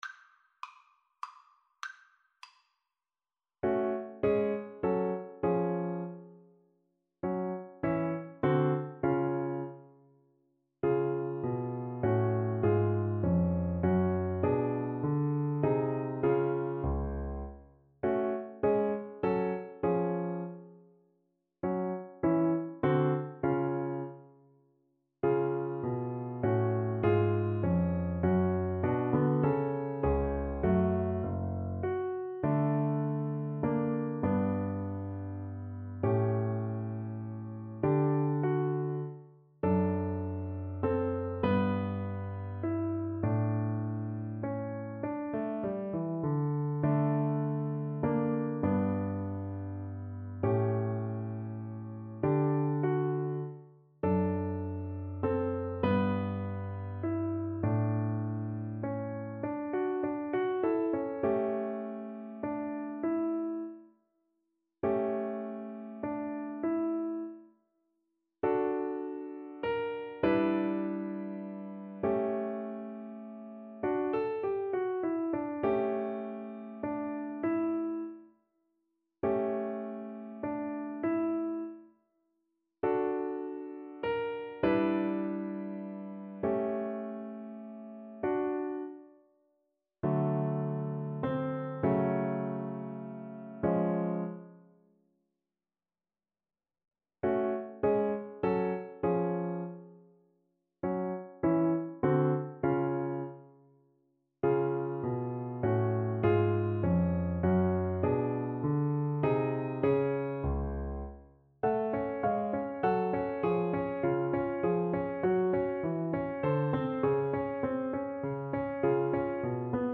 Allegretto
3/4 (View more 3/4 Music)
Classical (View more Classical Viola Music)